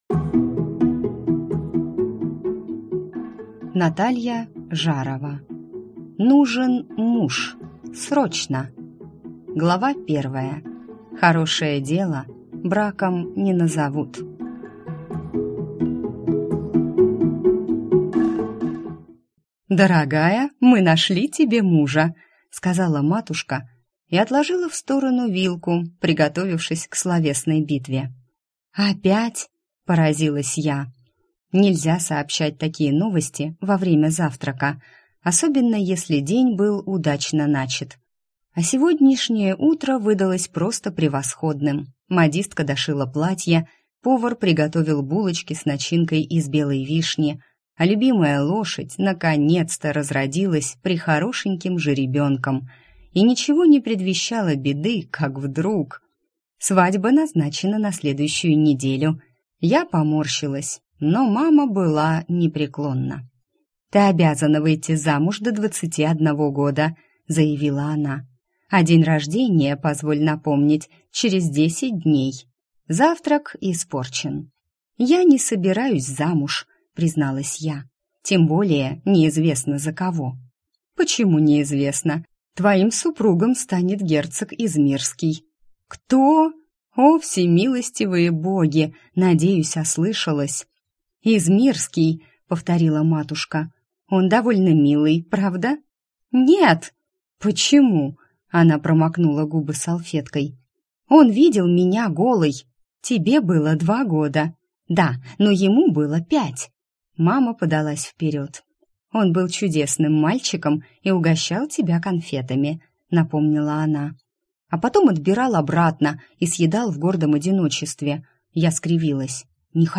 Аудиокнига Нужен муж! Срочно!